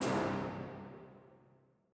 fye brass.wav